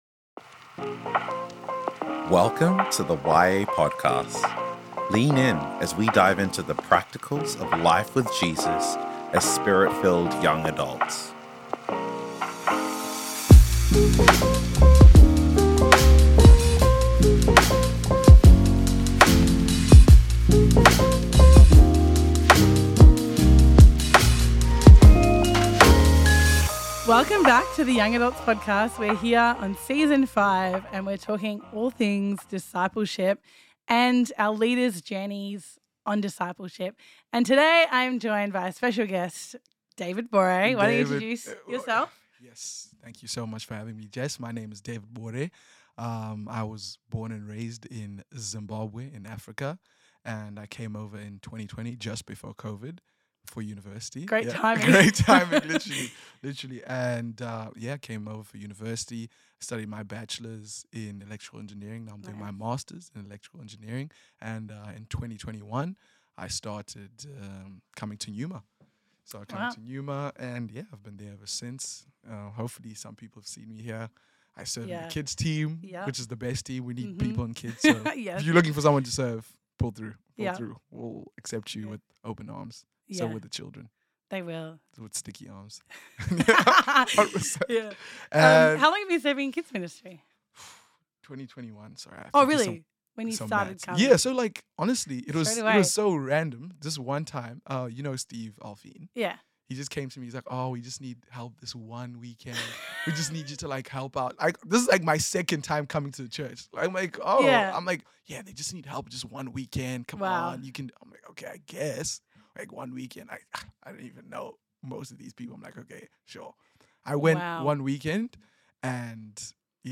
Discipleship Conversations: Patience and Sacrifice